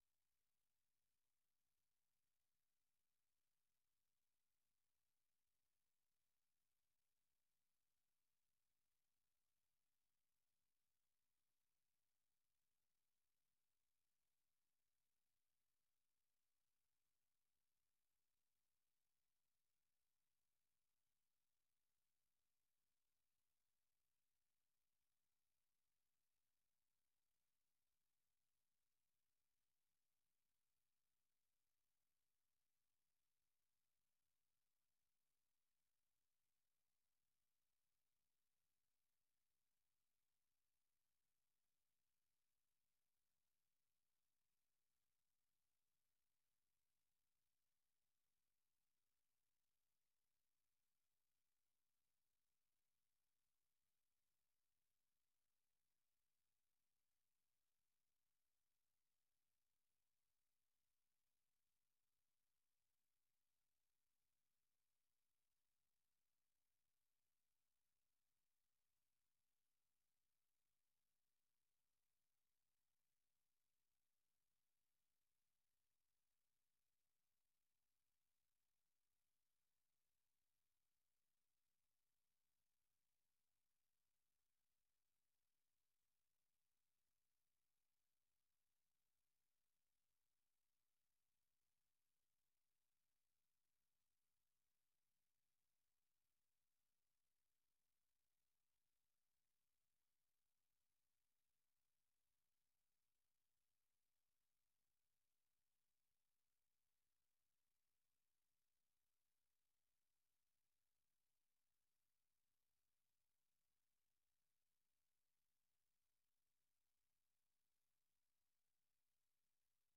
Ejo ni ikiganiro cy'iminota 30 gitegurwa n'urubyiruko rwo mu Rwanda, kibanda ku bibazo binyuranye ruhura na byo. Ibyo birimo kwihangira imirimo, guteza imbere umuco wo kuganiro mu cyubahiro, no gushimangira ubumwe n'ubwiyunge mu karere k'ibiyaga bigari by'Afurika.